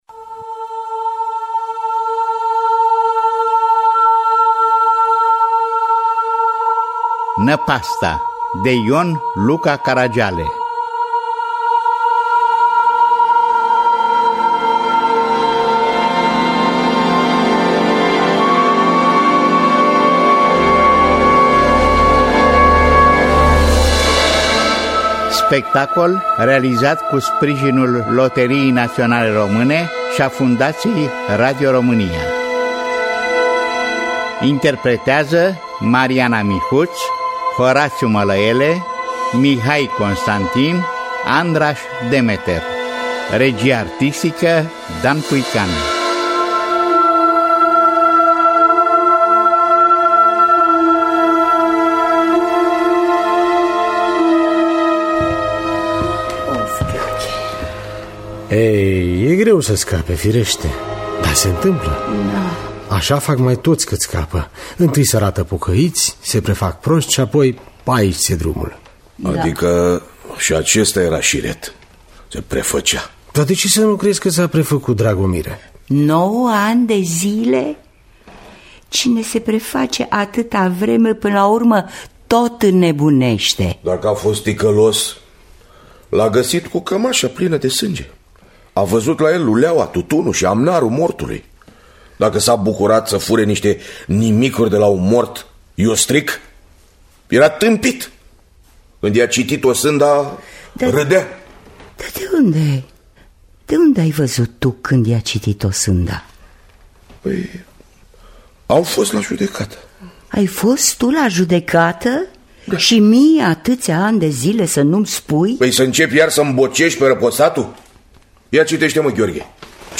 Năpasta de Ion Luca Caragiale – Teatru Radiofonic Online
Adaptarea radiofonică